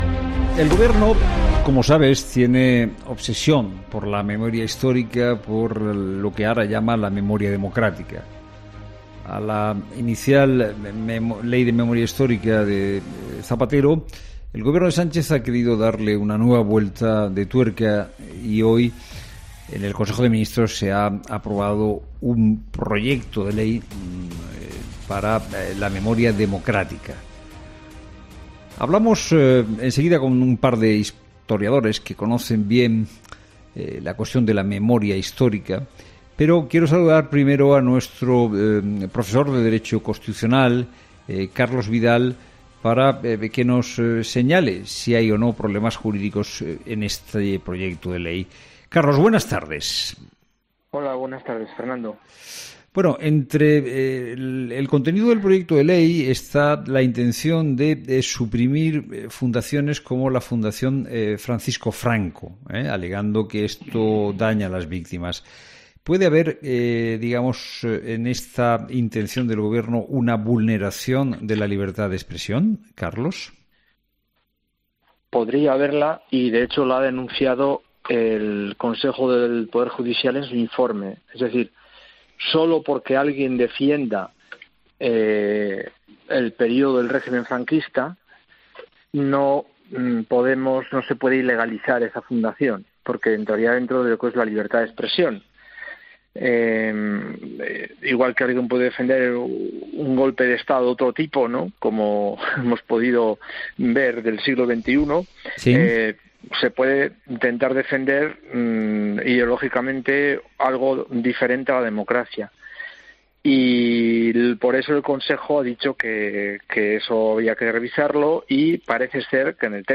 AUDIO: Los expertos han analizado en 'La Tarde' la decisión del Consejo de Ministros de aprobar la Ley de Memoria Democrática